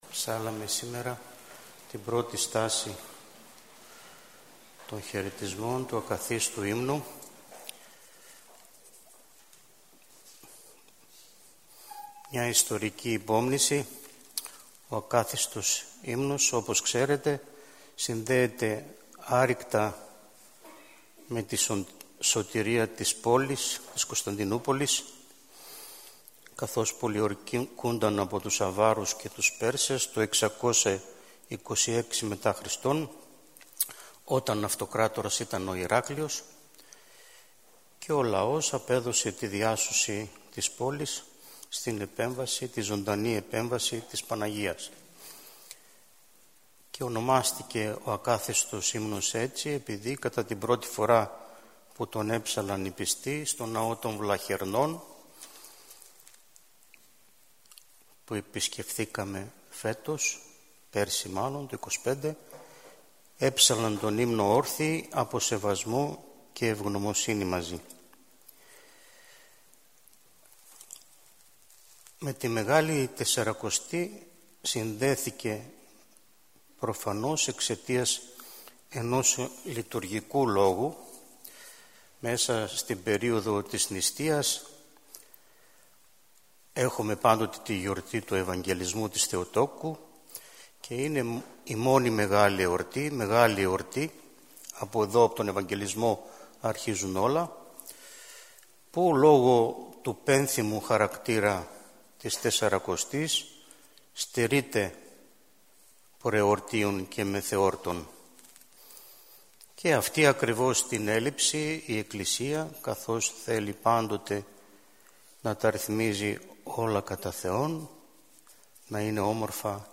Ὁμιλία